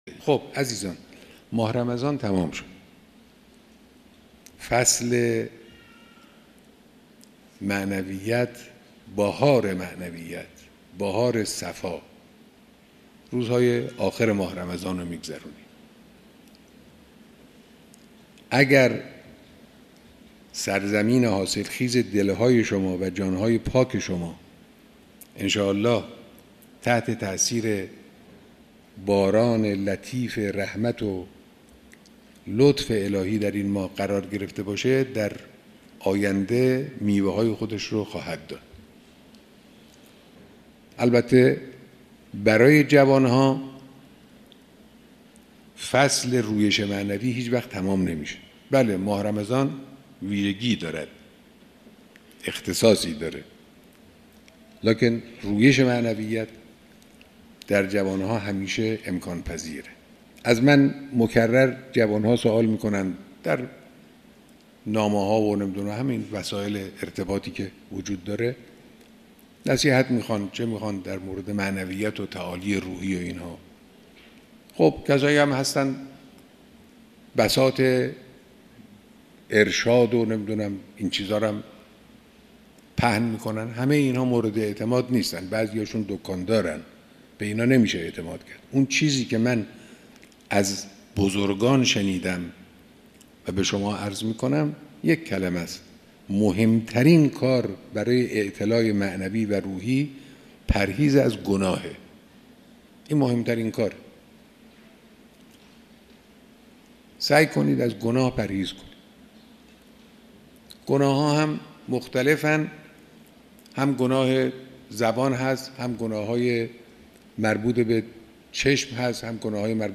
موسیقی سخنرانی